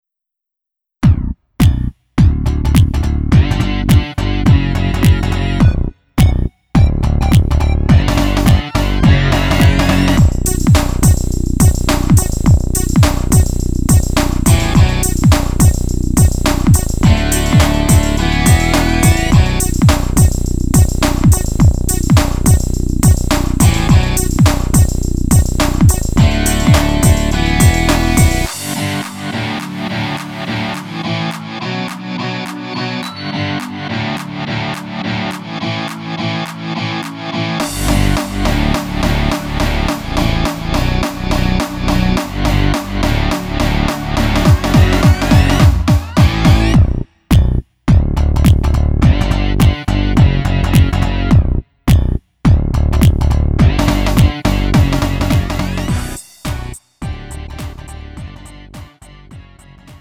음정 -1키 3:17
장르 가요 구분 Lite MR
Lite MR은 저렴한 가격에 간단한 연습이나 취미용으로 활용할 수 있는 가벼운 반주입니다.